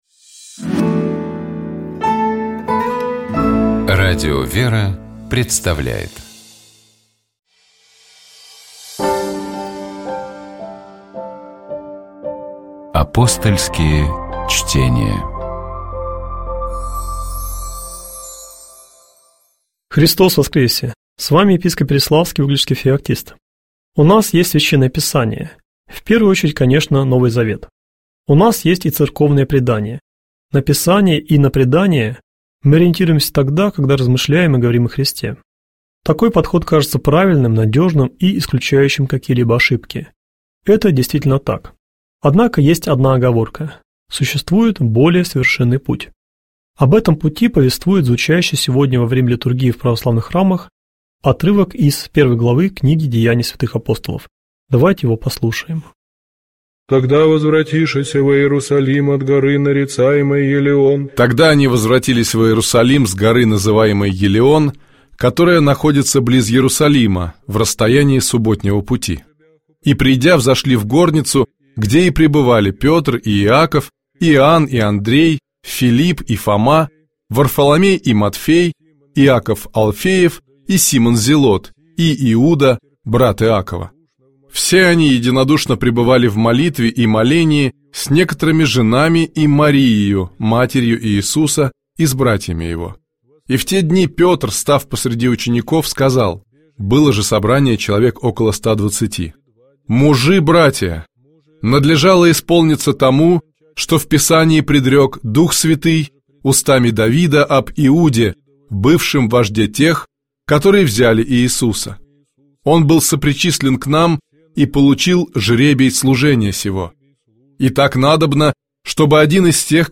Комментирует епископ Переславский и Угличский Феоктист.